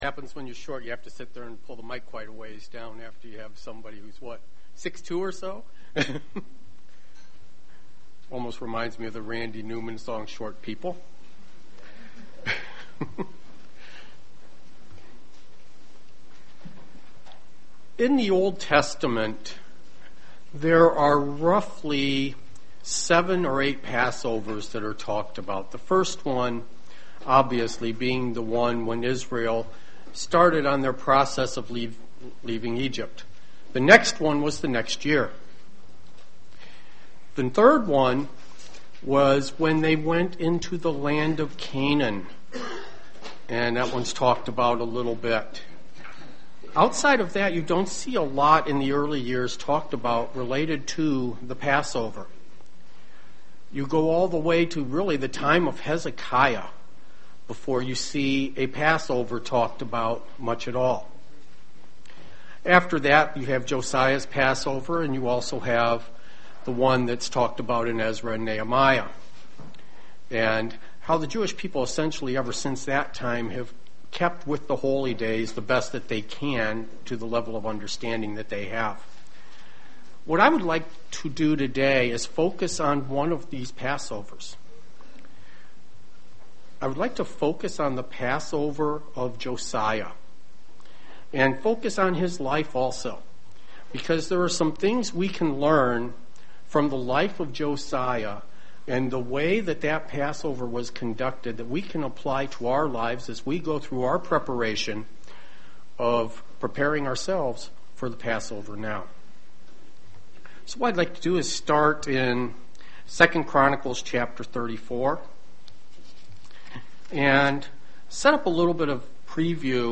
Split sermon looking at the heart of Josiah.